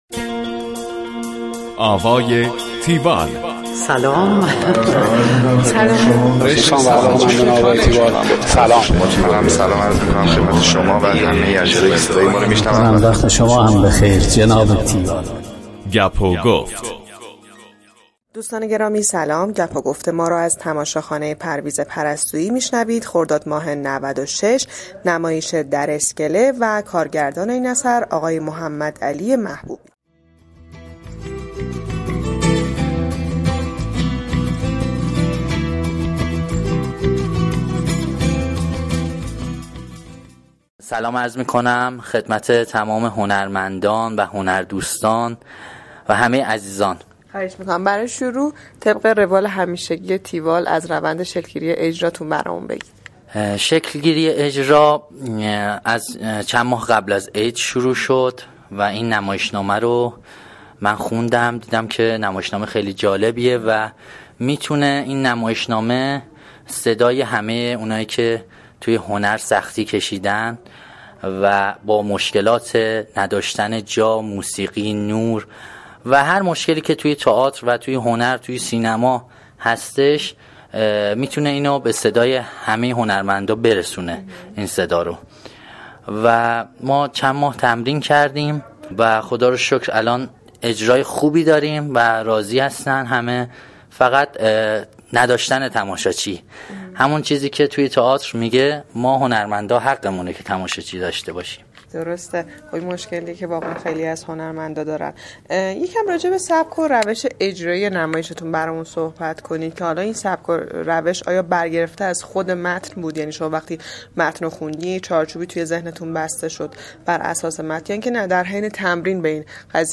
گفتگو کننده: